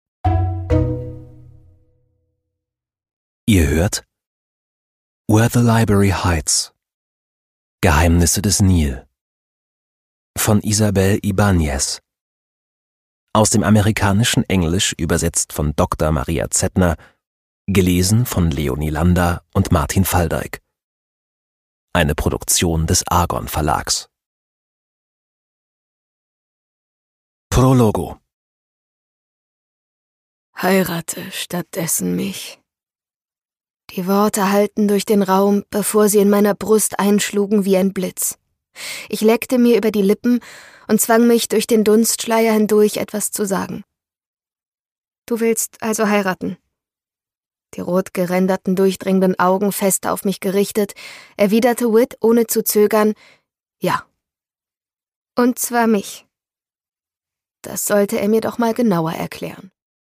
Isabel Ibañez: Where the Library hides - Geheimnisse des Nil, Band 2 (Ungekürzte Lesung)
Produkttyp: Hörbuch-Download